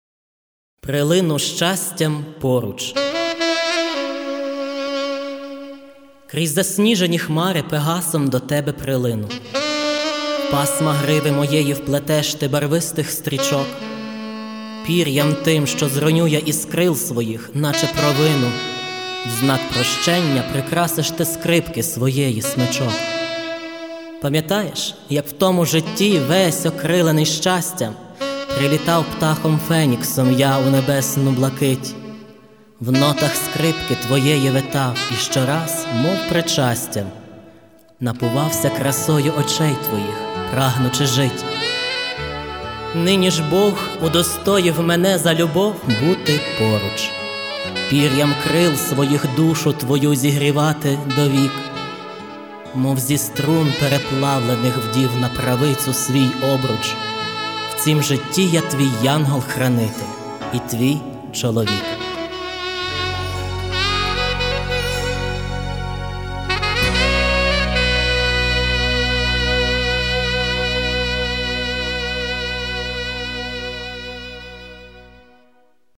150 примірників збірки випущено із диском-додатком з низкою віршів у авторському виконанні, серед яких і цей...
У ВАС ХОРОША ДЕКЛАМАЦІЯ, АЛЕ В ДАНОМУ ВИПАДКУ САКСОФОН ЗВУЧИТЬ ЗОВСІМ ОКРЕМО - РИТМ І ТЕМП НЕ СПІВПАДАЮТЬ ІЗ ОЗВУЧКОЮ ВІРША І, НА ЖАЛЬ, МУЗИКА НАВІТЬ ЗАВАЖАЄ У СПРИЙМАННІ ДЕКЛАМАЦІЇ...